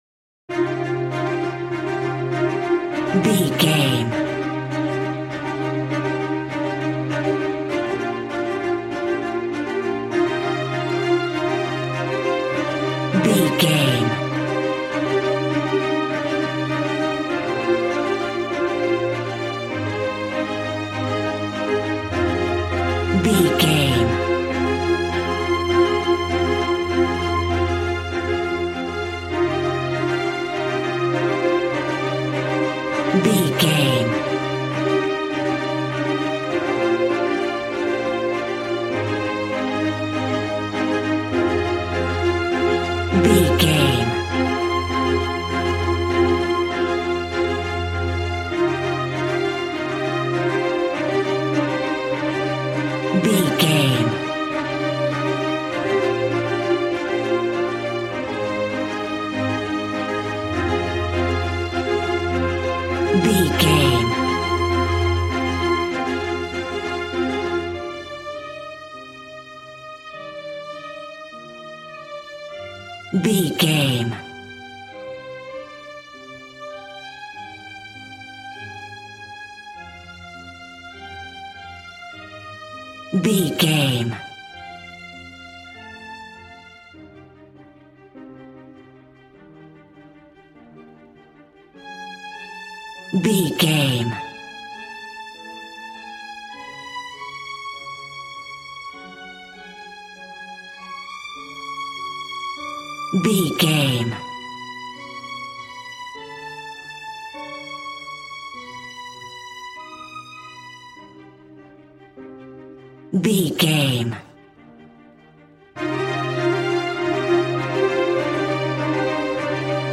Regal and romantic, a classy piece of classical music.
Aeolian/Minor
regal
cello
violin
strings